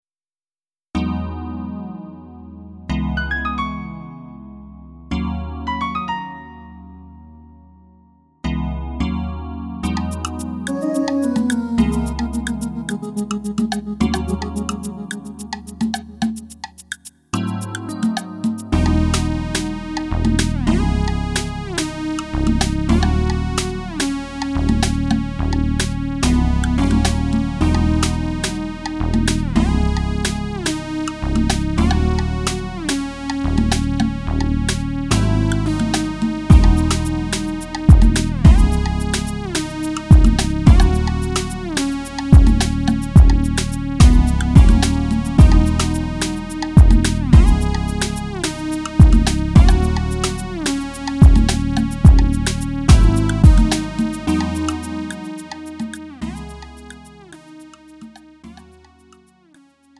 음정 -1키 3:19
장르 가요 구분